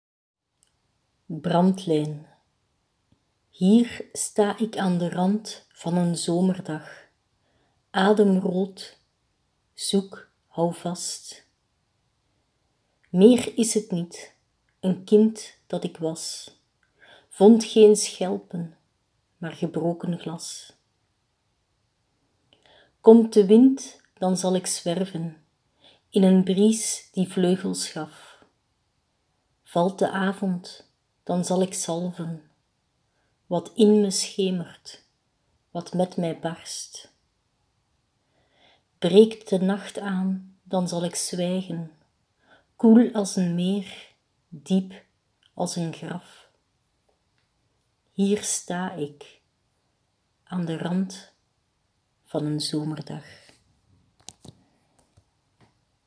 leest 'Brandlijn' voor